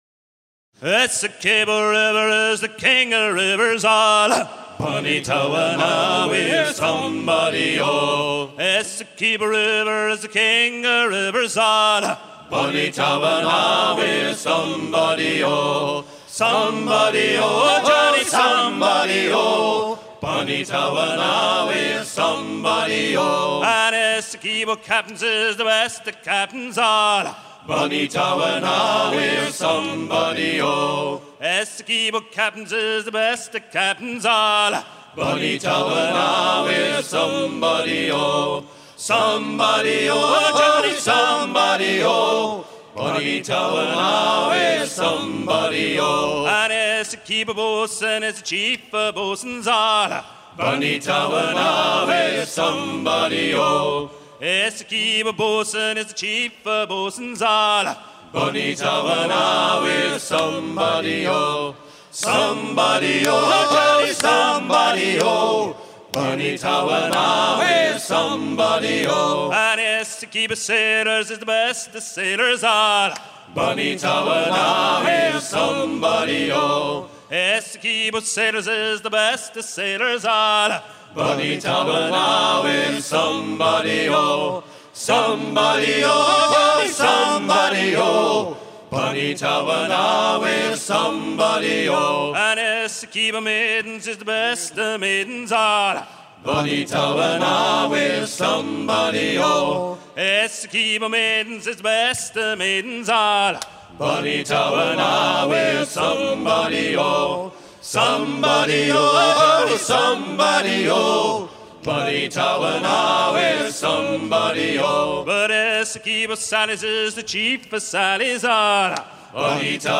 à hisser main sur main
Chants de marins en fête - Paimpol 1999
Pièce musicale éditée